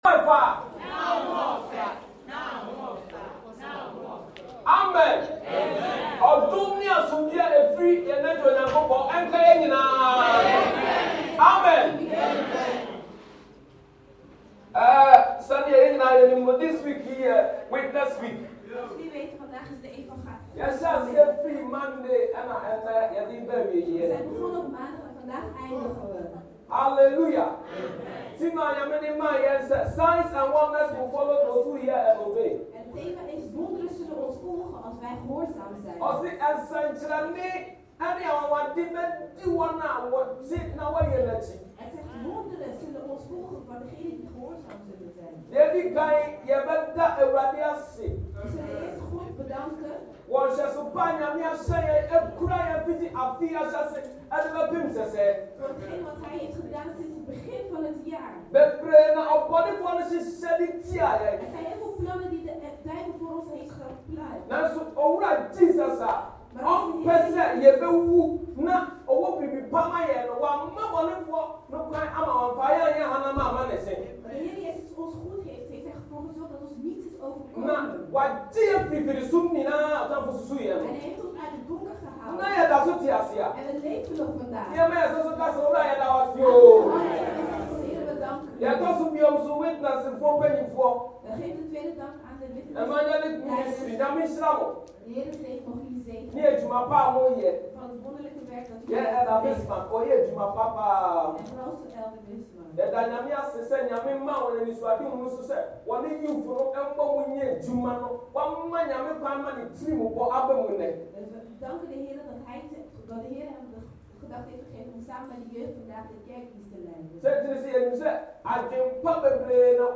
Dutch Assembly